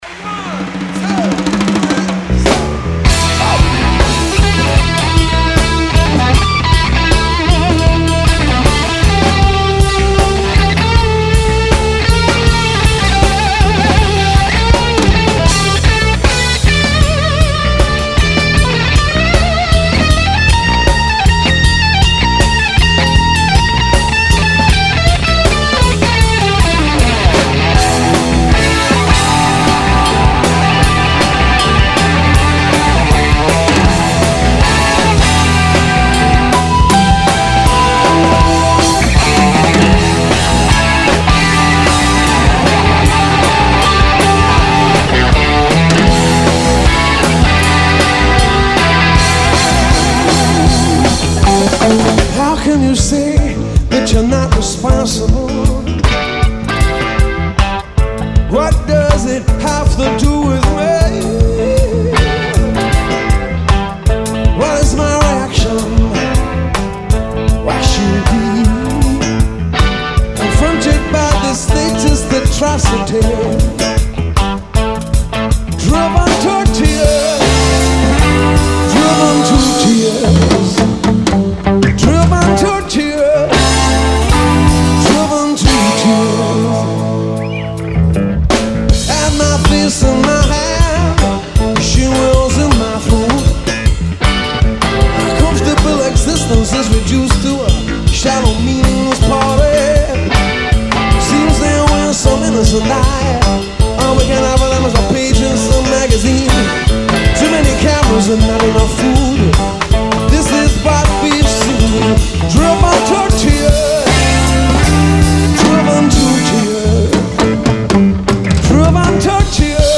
massive audience witnessing the reunion tour